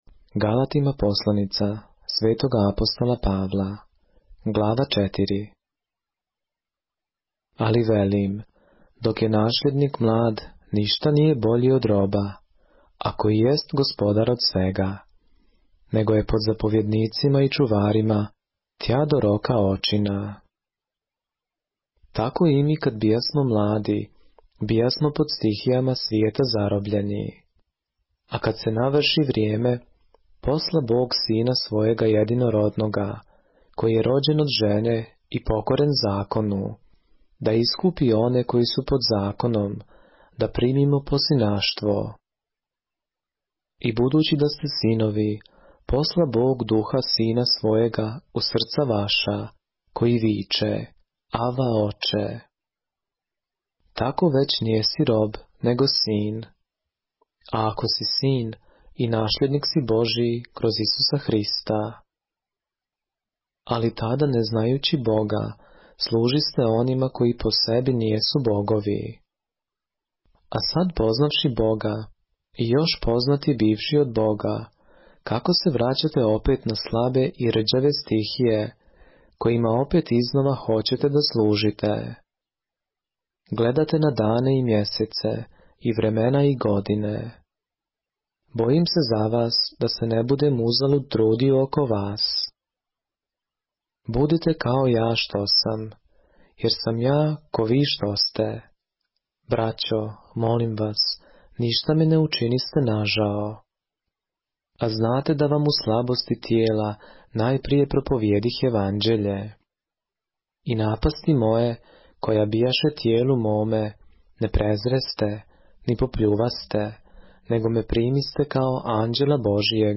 поглавље српске Библије - са аудио нарације - Galatians, chapter 4 of the Holy Bible in the Serbian language